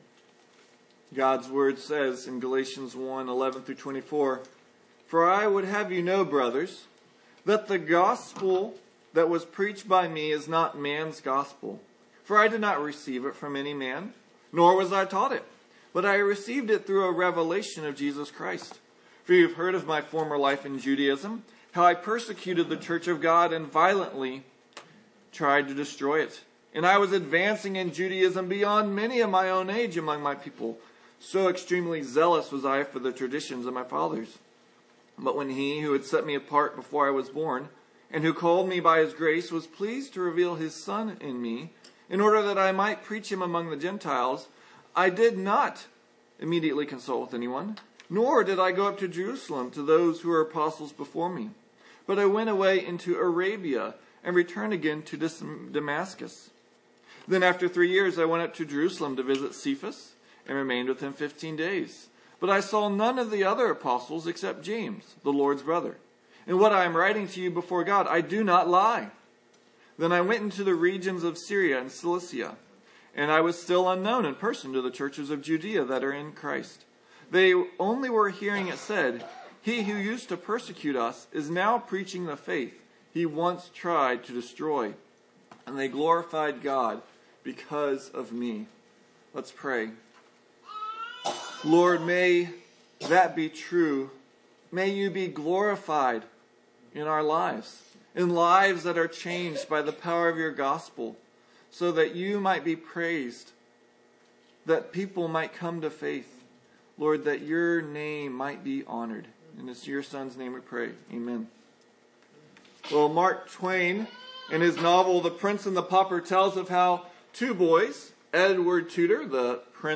Adult Bible Study %todo_render% « Who Do You Serve?